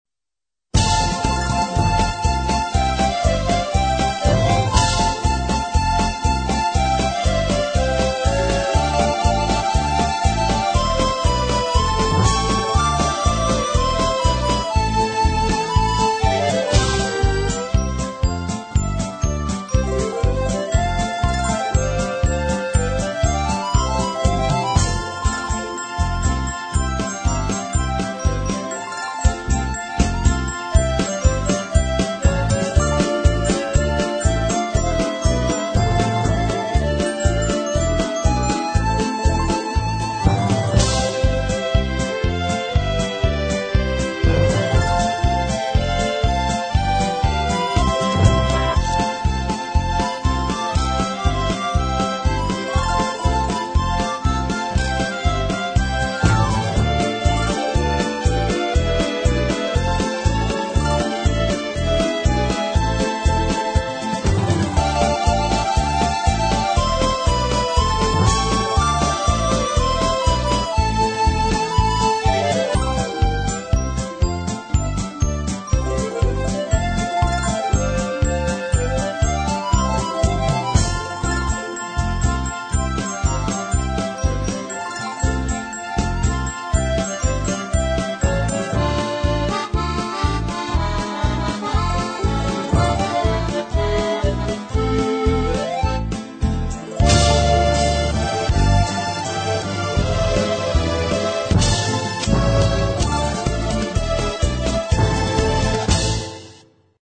Категорія: Мінусовки